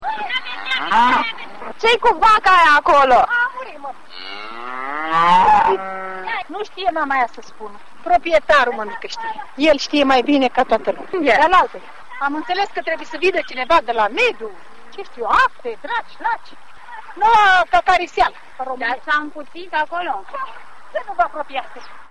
audio localnica slobozia noua